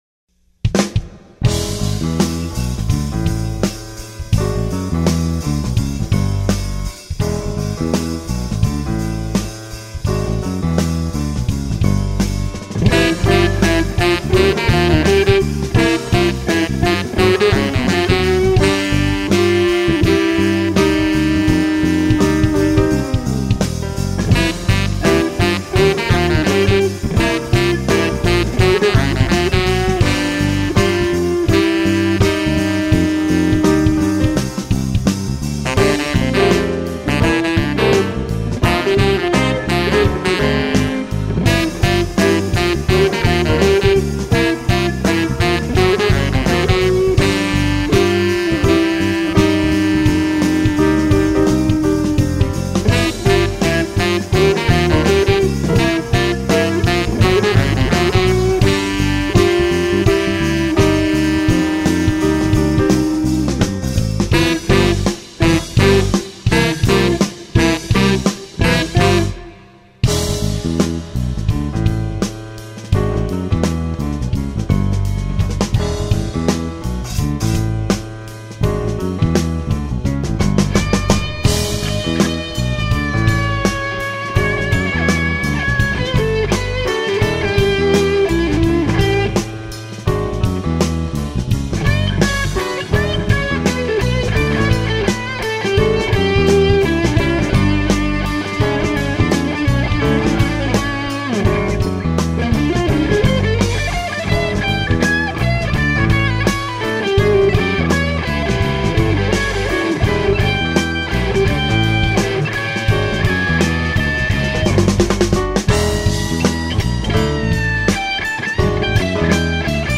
Keyboards
Drums
Bass
Tenor Sax
Guitar